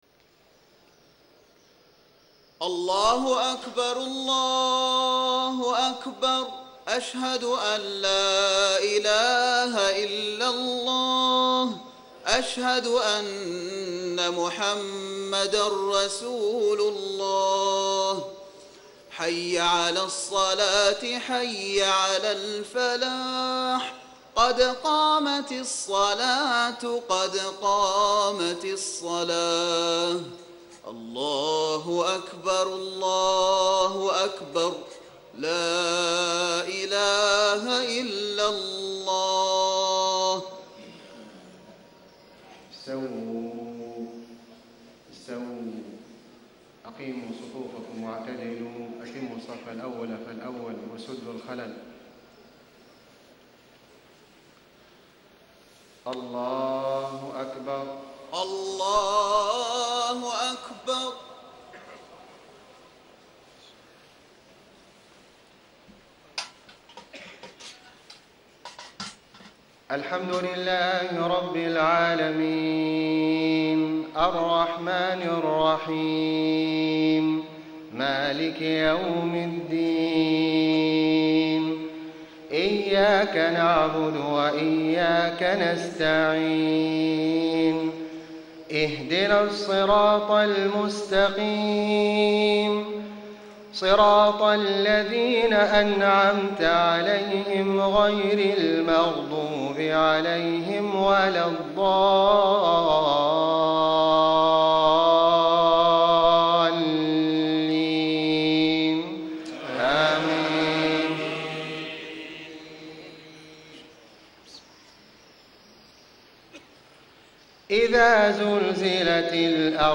صلاة المغرب 2-5-1435 سورتي الزلزلة و الهمزة > 1435 🕋 > الفروض - تلاوات الحرمين